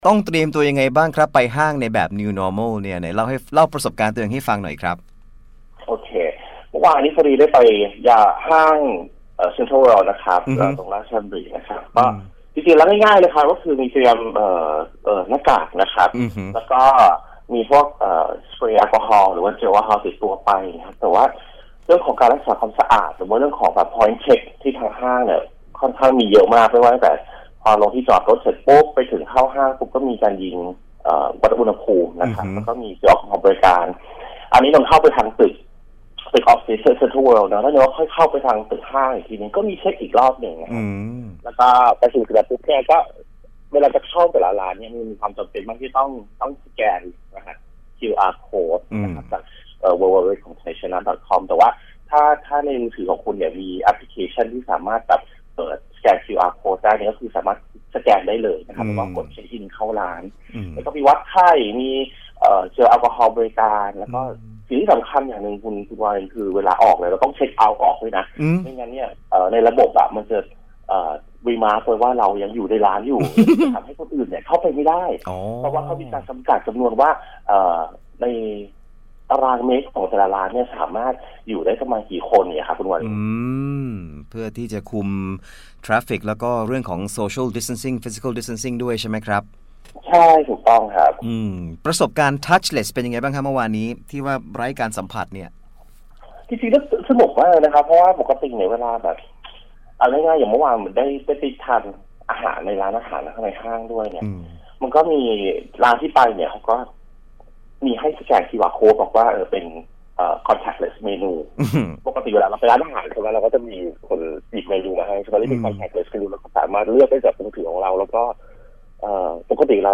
สัมภาษณ์